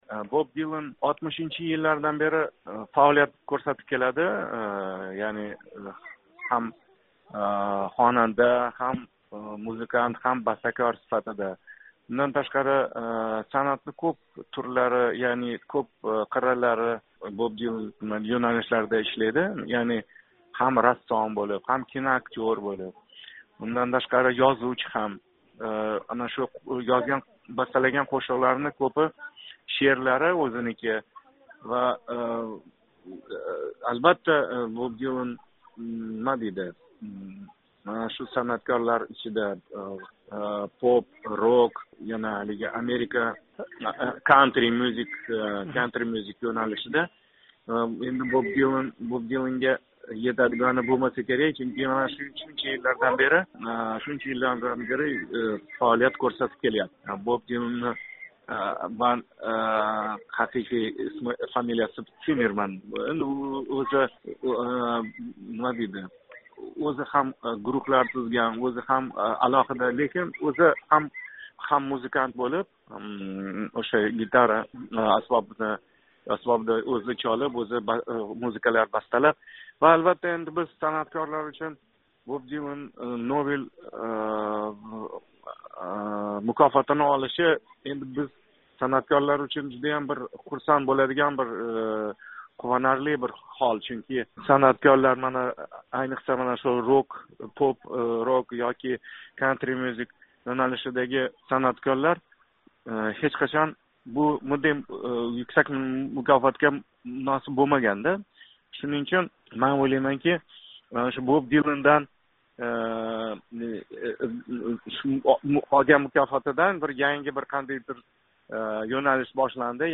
Ўзбекистонлик таниқли хонанда Мансур Тошматов Озодлик билан суҳбатда 2016 йилги адабиёт бўйича Нобел мукофоти америкалик машҳур қўшиқчи Боб Диланга берилганидан илҳомланганини айтди.